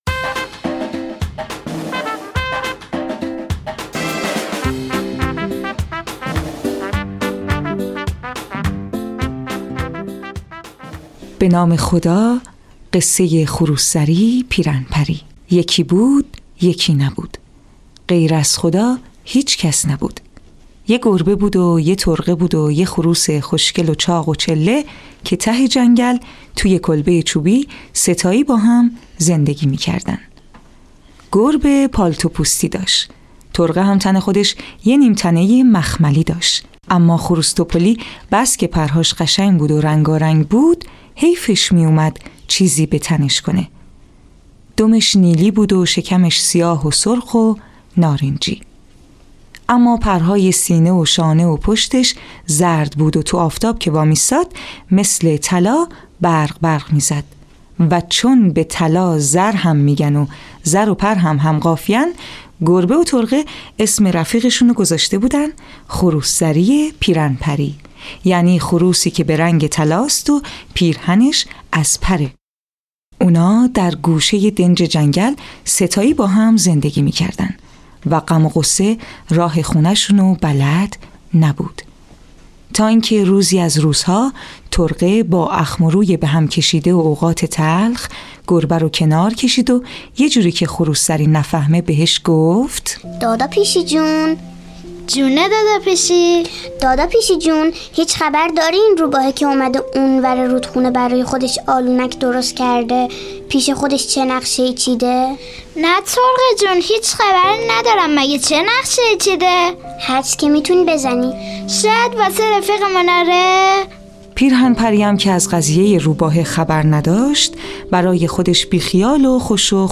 قصه های کودکانه صوتی- این داستان: خروس زری پیرهن پری
تهیه شده در استودیو نت به نت